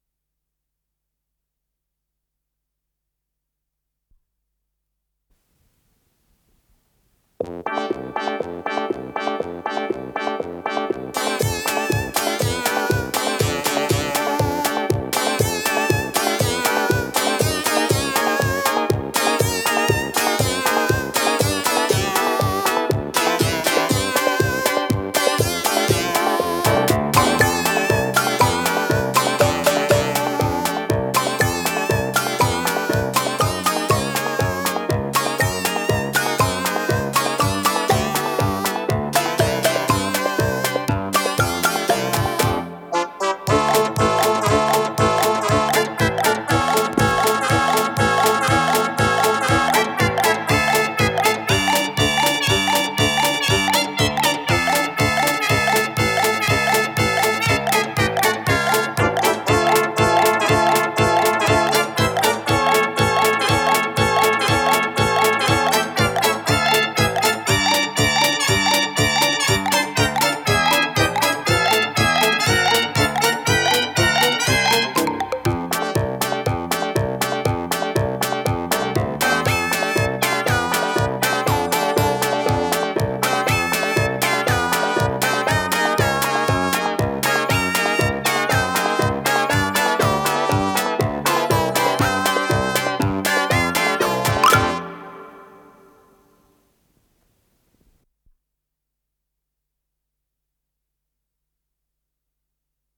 с профессиональной магнитной ленты
ударные
синтезатор